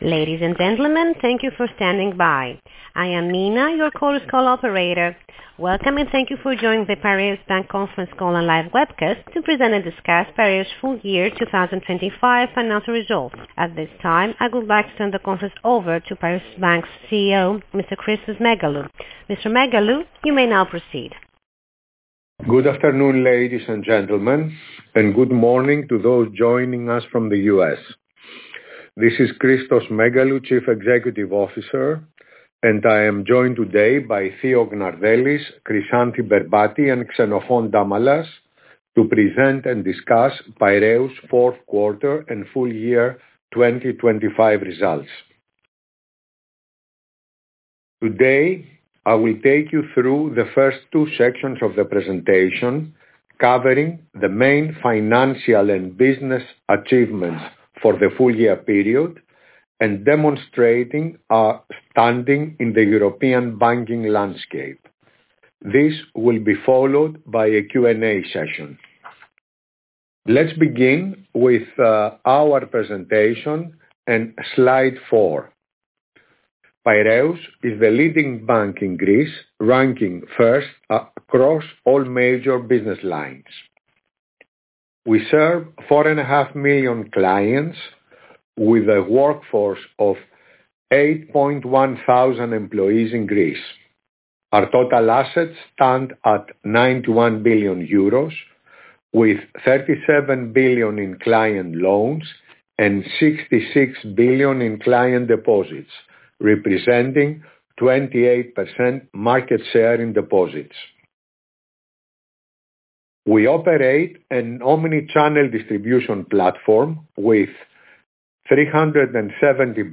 Conference call audio file